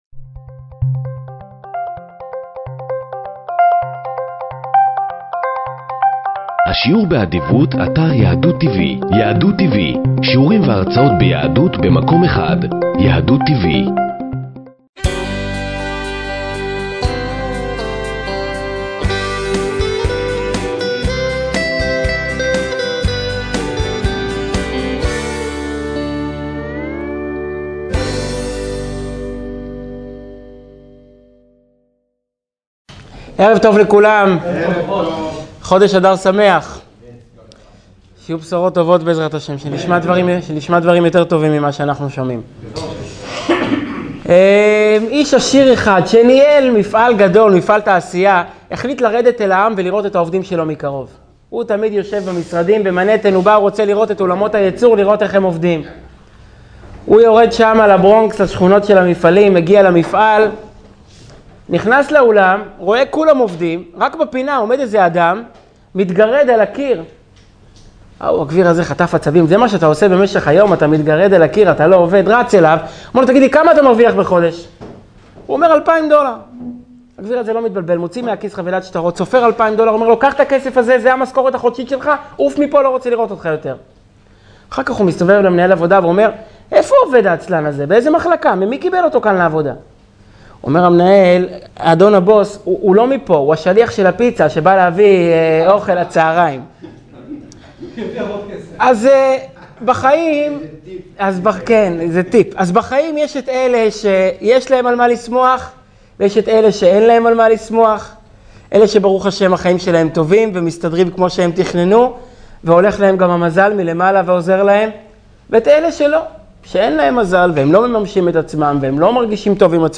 שיעור מרתק לפרשת תצווה-חודש אדר